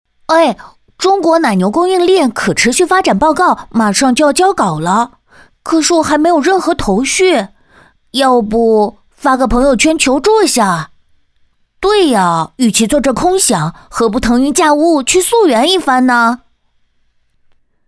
童声